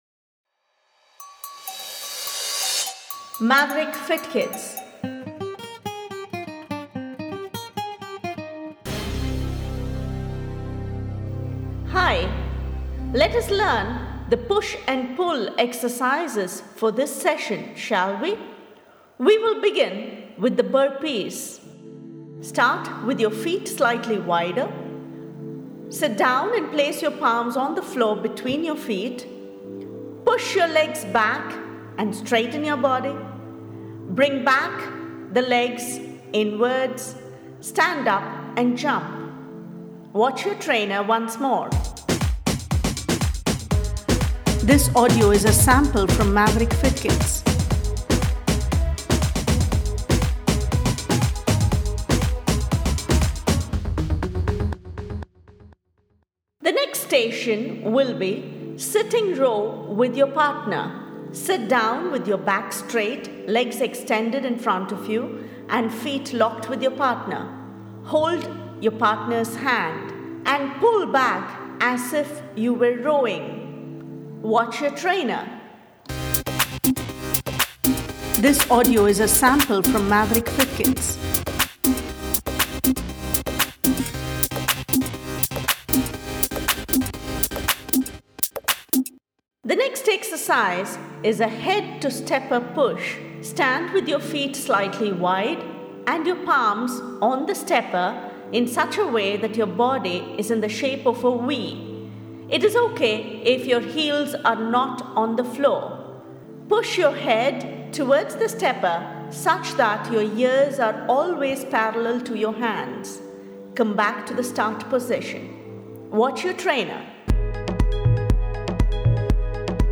DO NOT INCREASE NUMBER OF STUDENTS PER COLUMN AS THE MUSIC HAS BEEN TIMED FOR ONLY 7 PAIRS OF STUDENTS IN EACH COLUMN.
Demonstrate the following as the instructions play out Partner Push & Pull instructions